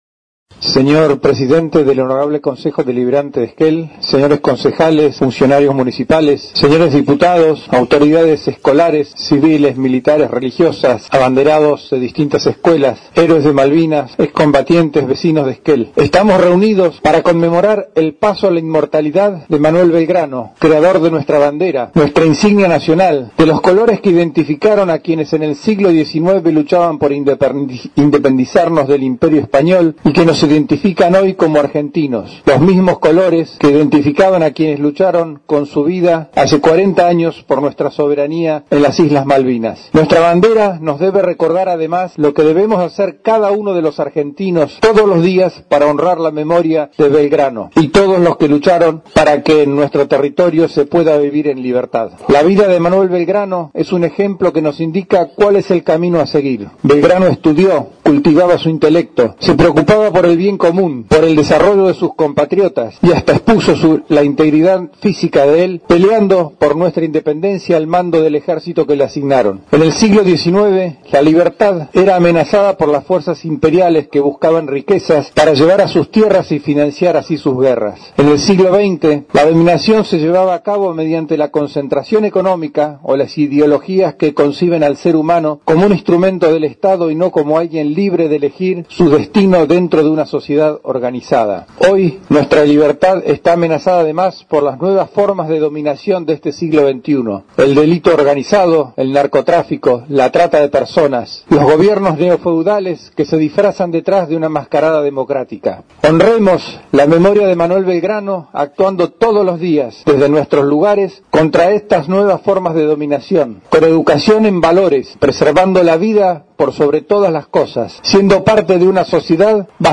En la plazoleta de Avenida Alvear al 150, donde se encuentra el busto que recuerda al General Manuel Belgrano, se hizo el acto oficial al conmemorarse el 203° aniversario desde su paso a la inmortalidad.
El discurso central estuvo a cargo del intendente Ongarato.